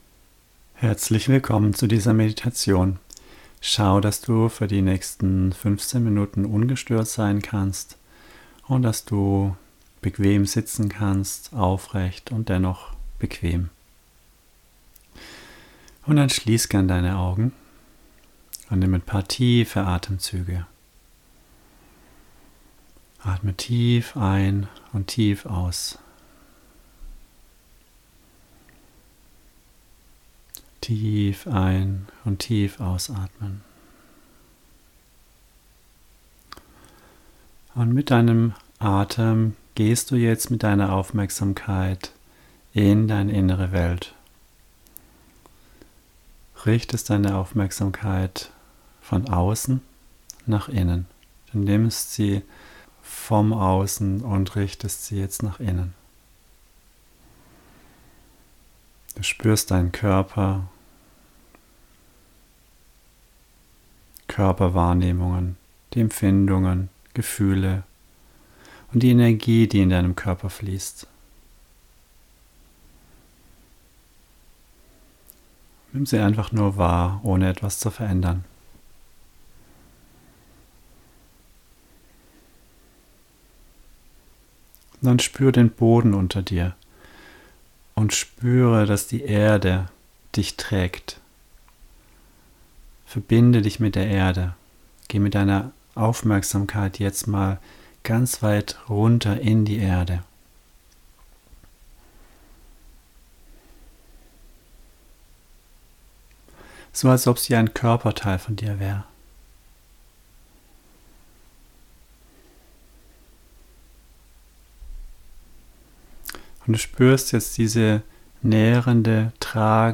Deine Meditation zum Download Hier kannst du meine heilsame, geführte Meditation „Dein geheiltes Ich“ machen bzw downloaden.